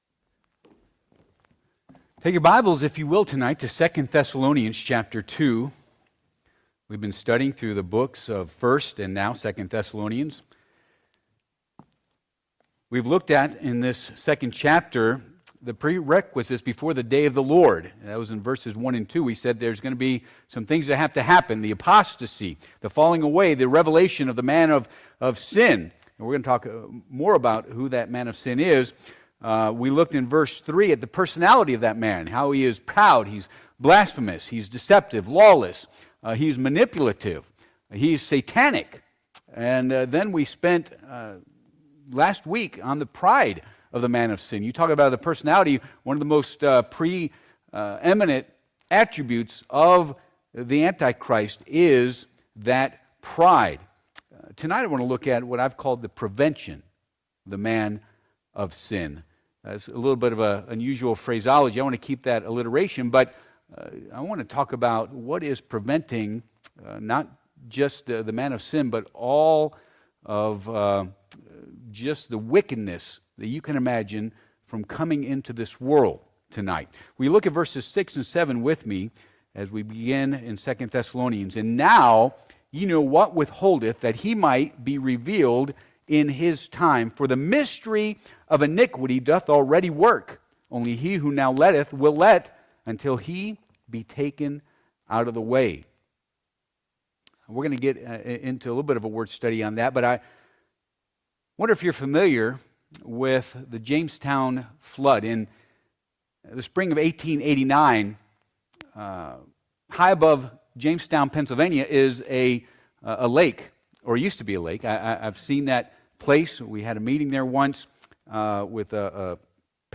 Passage: 2 Thess. 2:6-7 Service Type: Midweek Service